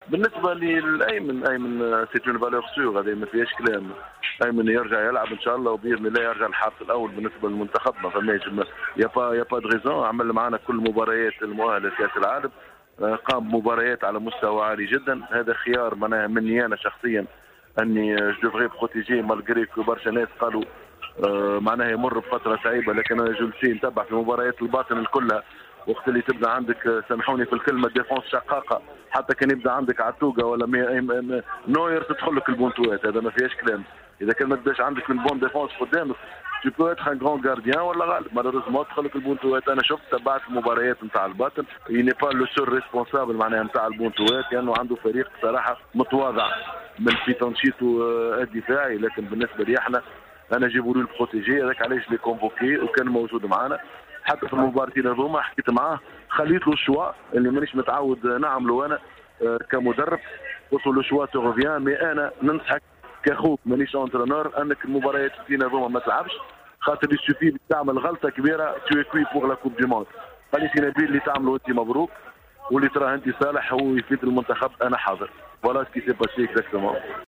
خلال مداخلته في برنامج ' قوول '